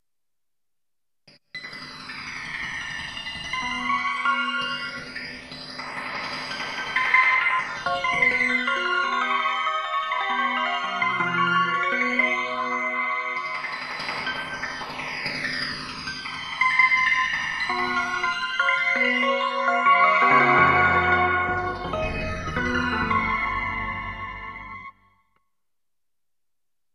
SERIALISTIC MICROTONALITY
IN ANY CHROMATIC STYLE SCALE UP TO 1000EDO
SERIALISM, SPATIALISATION, DODECOPHANY, DISSONANT SCHOOLS